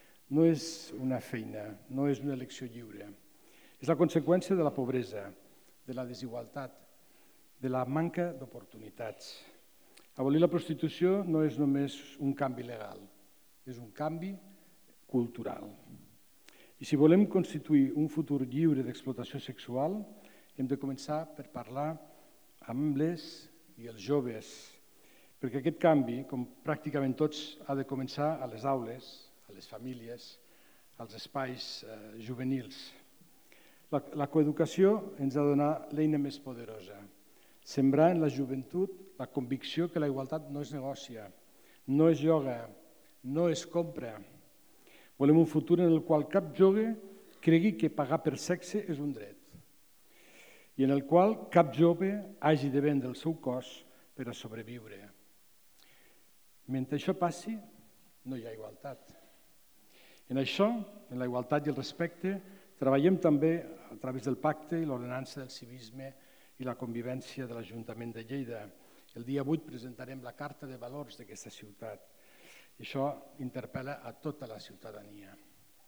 Unes 300 persones han assistit a la jornada Prostitució i explotació sexual de dones, nenes i nens: prevenció del consum de pornografia i la prostitució en l’era digital, a la Llotja de Lleida, organitzada des de la Regidoria de Polítiques Feministes de l’Ajuntament de Lleida en el marc del Dia Internacional contra l'Explotació Sexual i el tràfic de dones, nenes i nens, que cada any es commemora el 23 de setembre.